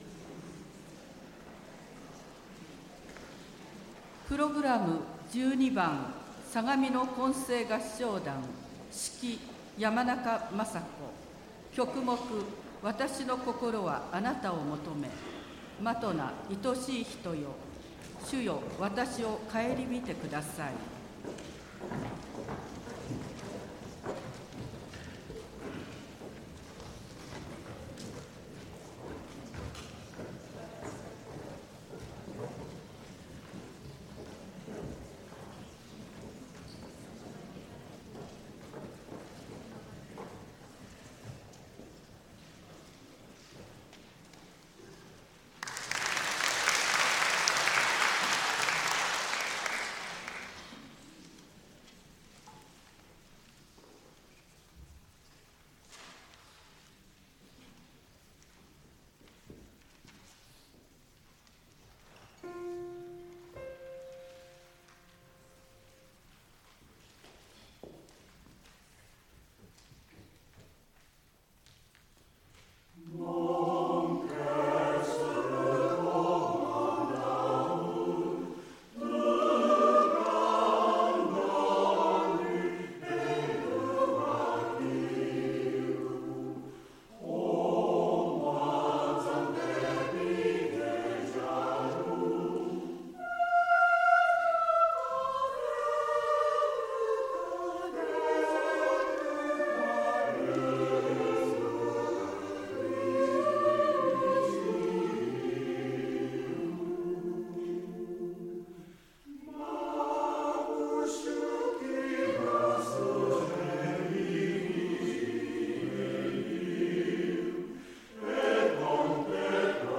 神奈川県相模原市で、アカペラのアンサンブルを楽しんでいます。
中世・ルネッサンスのポリフォニーの曲を中心に歌っています。
相模原市のミニ合唱祭”かがやけ歌声”に出演しました
(杜のホールはしもと)